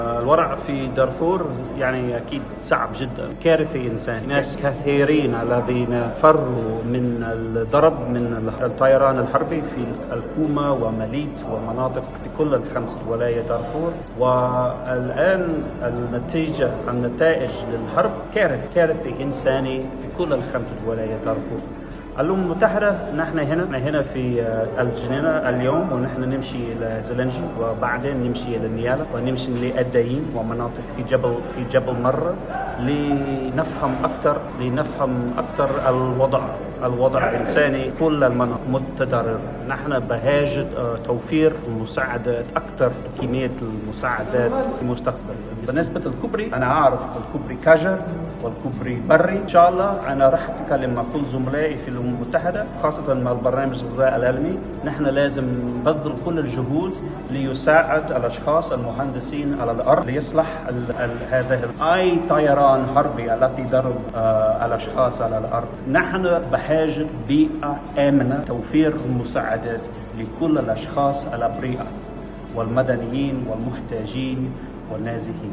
وقال توبي في تصريحات صحفية محدودة امس بالجنينة عقب لقاء رئيس الإدارة المدنية لولاية شرق دارفور، التجاني الطاهر كرشوم، (الوضع في دارفو صعب جدا ، وهناك كارثة إنسانية ، وهناك أيضا عدد من المواطنين فروا من الحرب وقصف الطيران الحربي ، في الكومة ومليط ، ومناطق اخري في الخمس ولايات ، وتابع توبي (نتائج الحرب الان كارثي هناك كارثة إنسانية ) ، وكشف توبي ان الأمم المتحدة بحاجة الي توفير المساعدات الإنسانية .تزايد النزوح من الفاشر، عاصمة شمال دارفور، إلى مناطق جبل مرة الخاضعة لسيطرة حركة تحرير السودان بقيادة عبد الواحد محمد، وسط استمرار الاشتباكات بين الجيش وقوات الدعم السريع.